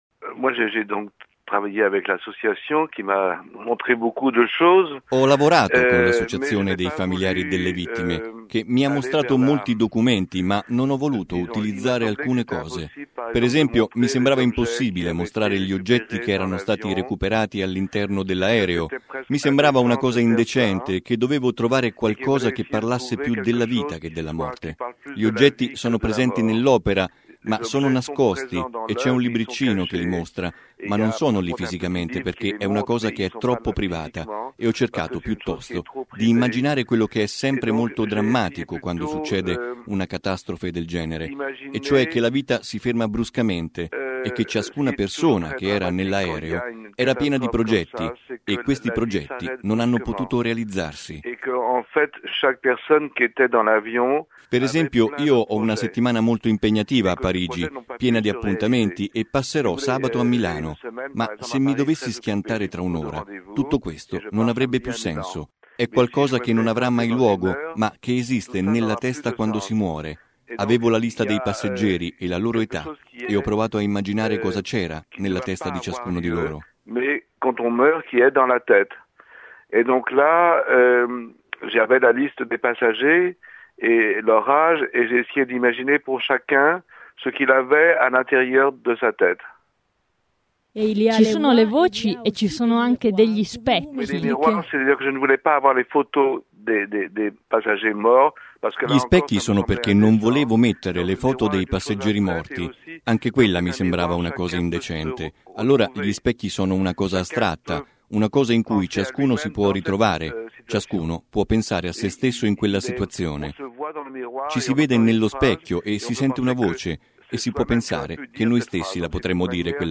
L’artista francese Christian Boltanski racconta ai nostri microfoni come ha realizzato l’installazione permanente del relitto del DC9 per il Museo per la Memoria di Ustica.